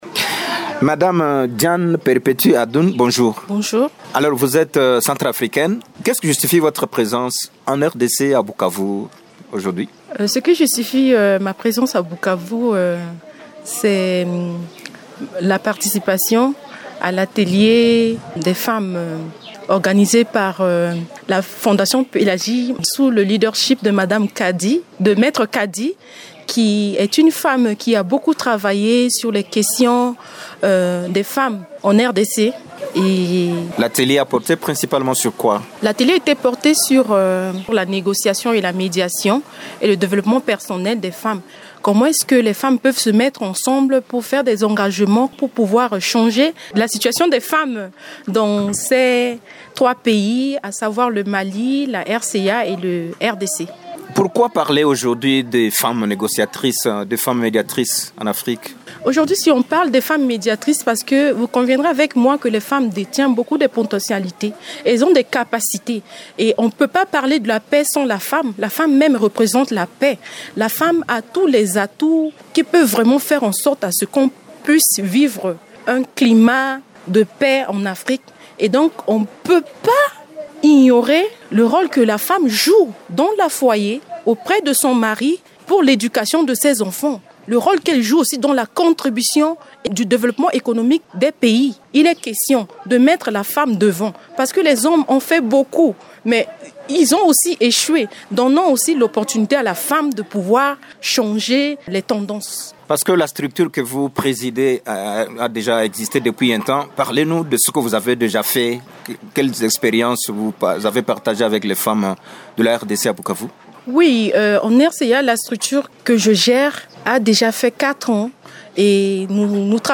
Elle fait cette recommandation au cours d’une interview à Radio Okapi, dimanche 11 juin.